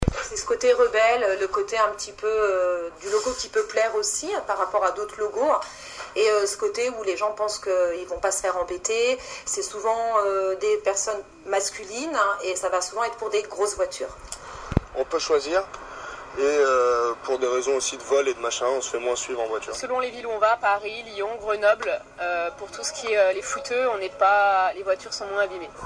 C’est ce qui transparaît dans ces témoignages: